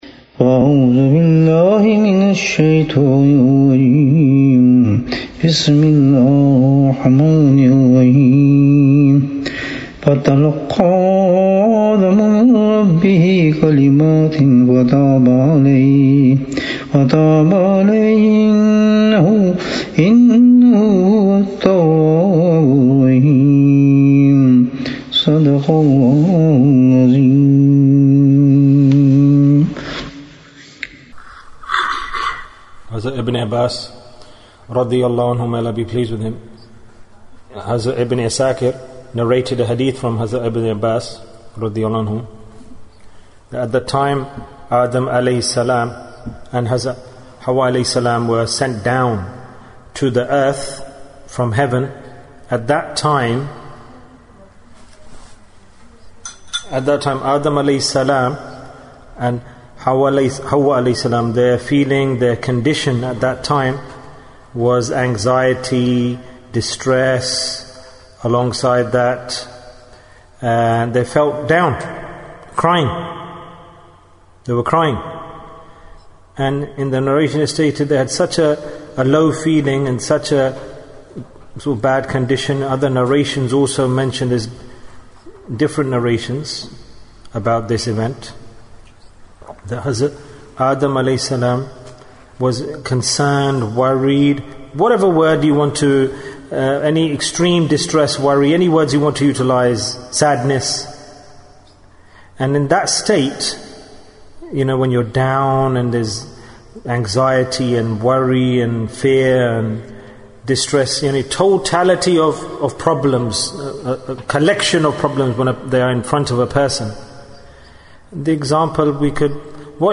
Greatest Gift Given to Bani Adam - Dars 41 Bayan, 44 minutes8th August, 2020